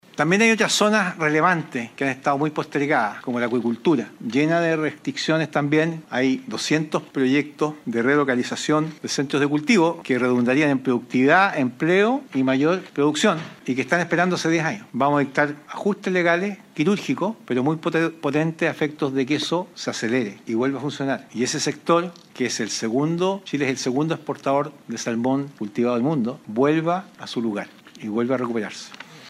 El ministro de Hacienda, Jorge Quiroz, presentó esta iniciativa, explicando que se harán cambios en las normas para acelerar proyectos que han estado esperando autorización por más de diez años. Según expuso, estos cambios serán “quirúrgicos” y están dirigidos a fomentar la inversión en el sector.
ministro-hacienda-proyectos-relocalizacion.mp3